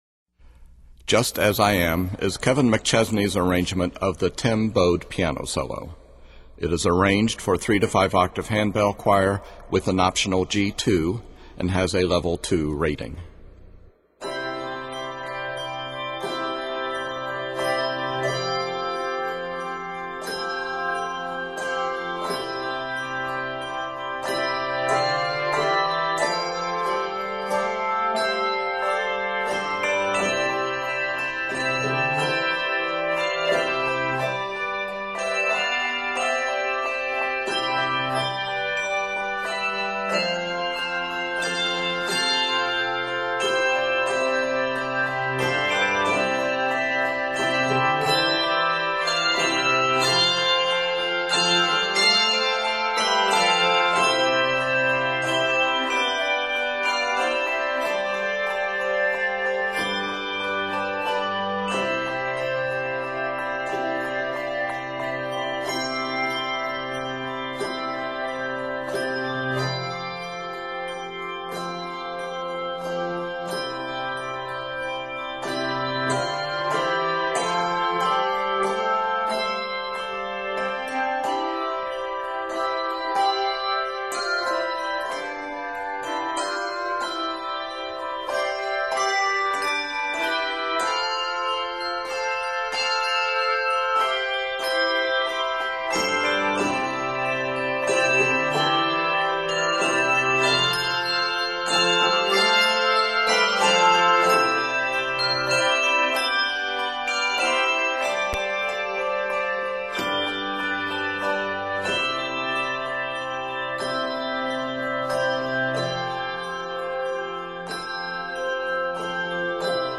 handbell piece